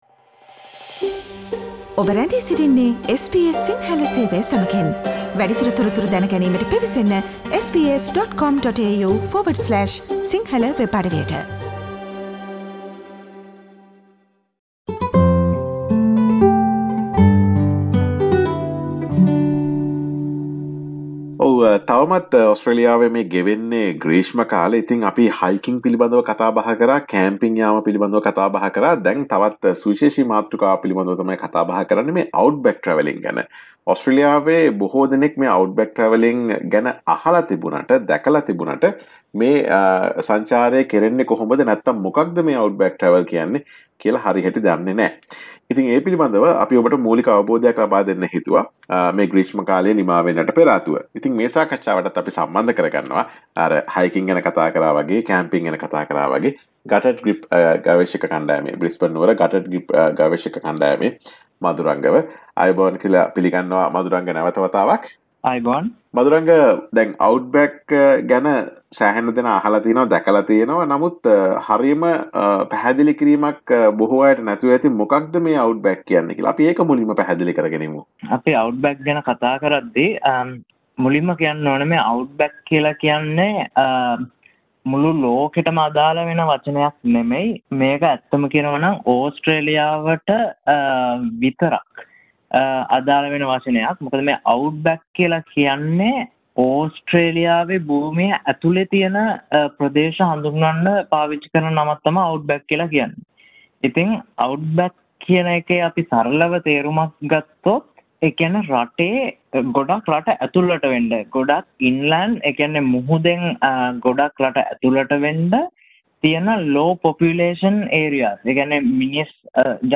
SBS සිංහල ගුවන් විදුලිය සිදුකළ සාකච්ඡාව.